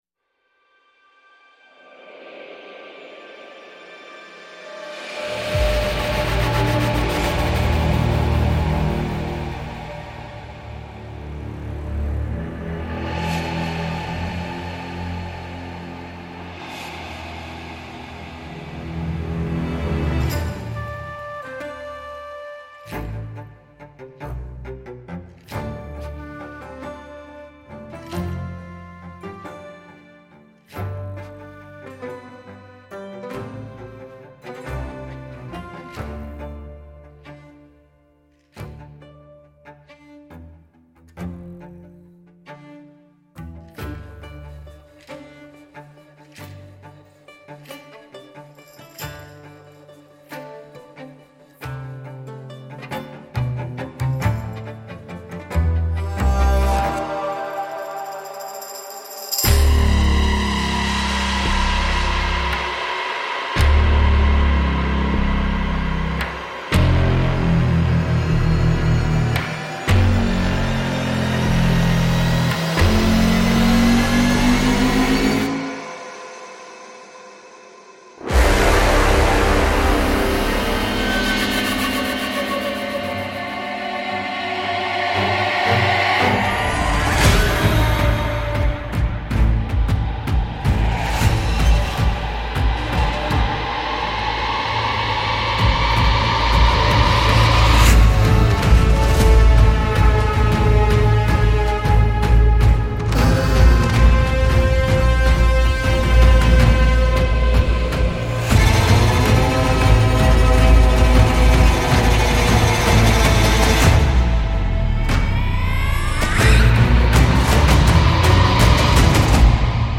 سبک موسیقی (Genre) موسیقی متن, موسیقی انیمه